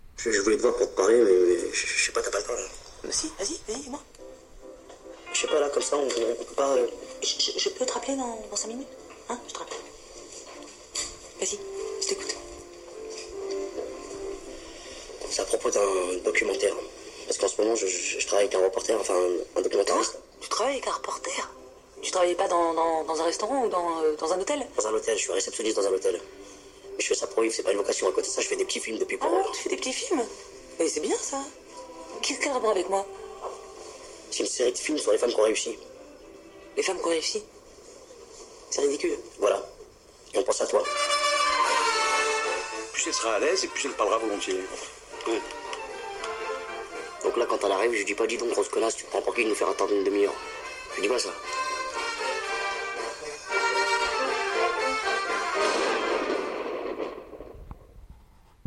Voici donc une comédie douce-amère pour passer un bon moment, avec de très bons acteurs, à condition de ne pas être agacé par le débit de parole de chacun d’entre eux ! Parce qu’il faut reconnaître que ça va vite et que les répliques fusent, comme toujours avec Agnès Jaoui et Jean-Pierre Bacri.